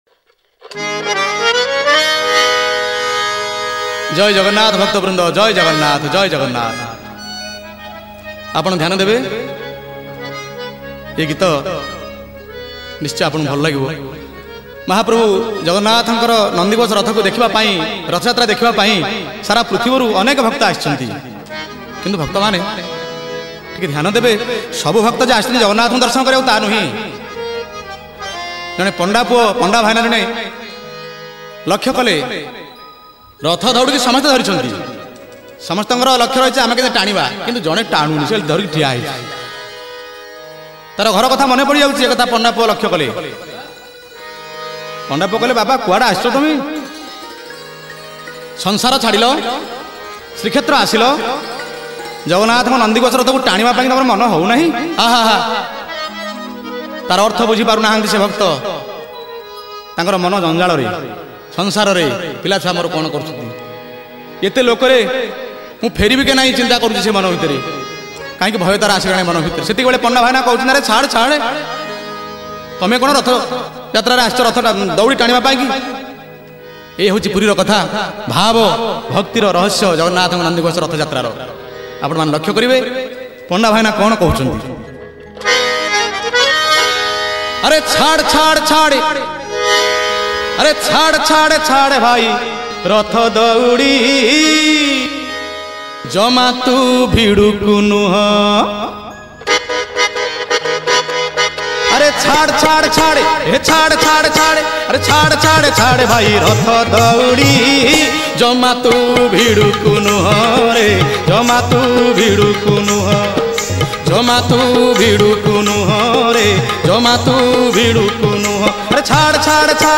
Ratha Yatra Odia Bhajan 2022 Songs Download